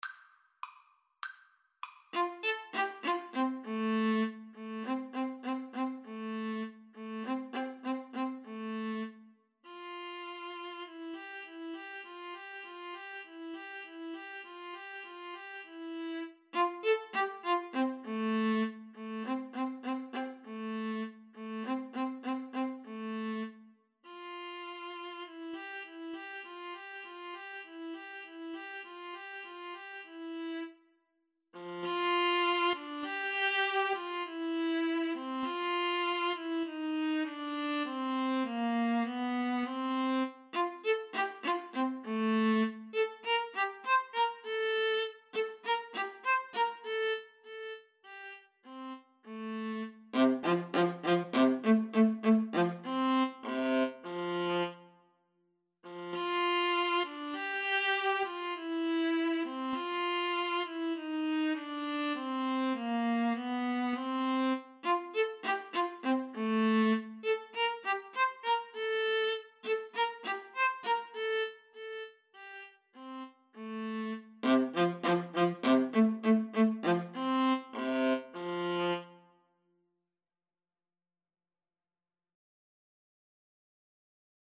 2/4 (View more 2/4 Music)
Classical (View more Classical Clarinet-Viola Duet Music)